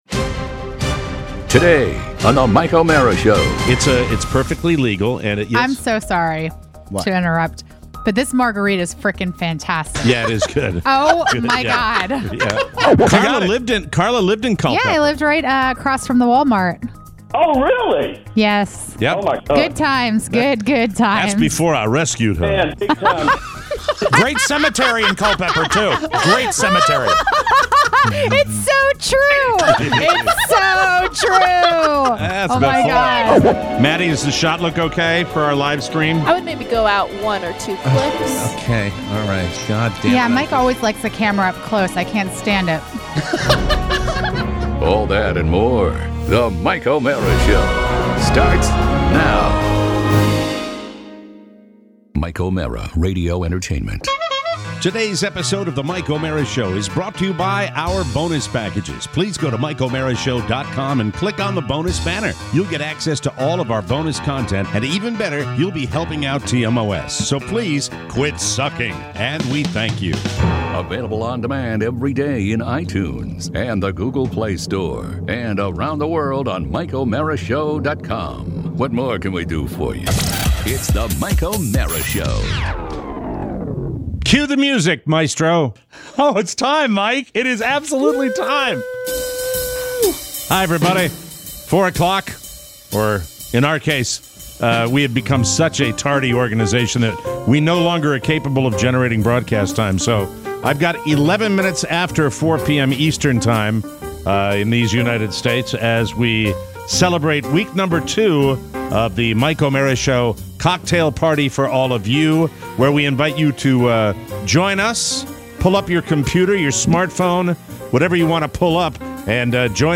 It’s the second TMOS Cocktail Hour. We take your calls and get more and more intoxicated.